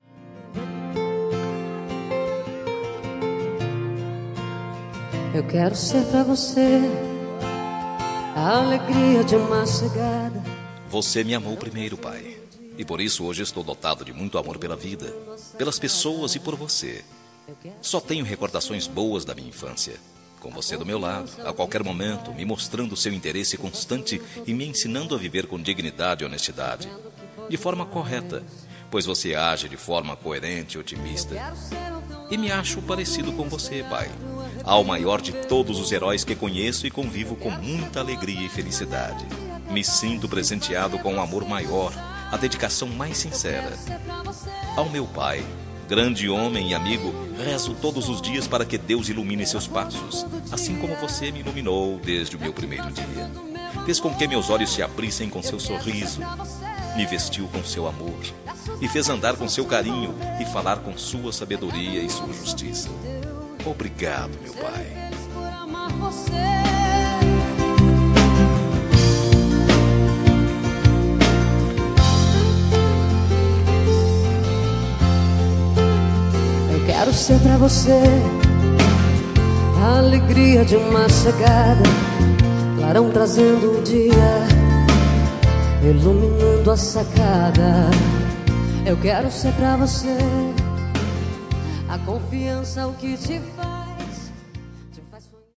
Temas com Voz Masculina